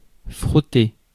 Ääntäminen
France: IPA: /fʁɔˈte/